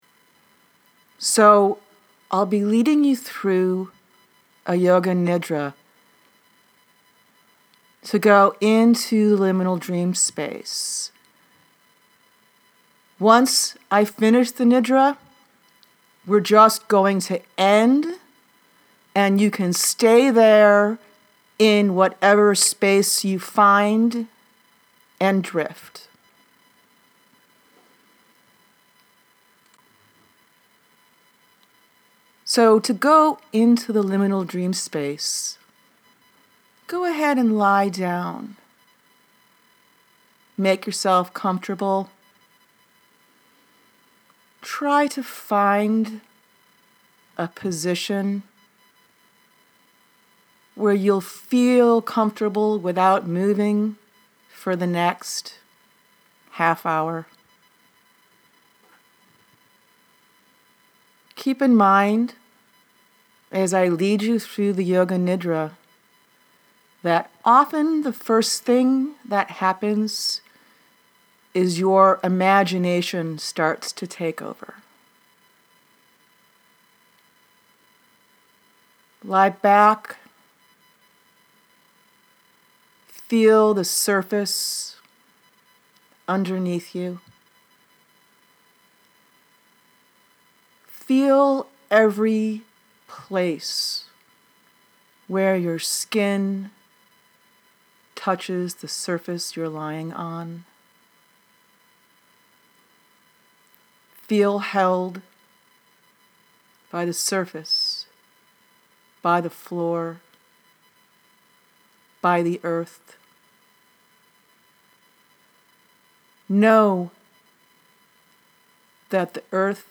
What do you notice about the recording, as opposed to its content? I close each dream circle with a yoga nidra meditation that also has to do with the month’s topic. During the December 2020 Oneironauticum Dream Circle, I led a yoga nidra meditation about using scent as an oneirogen.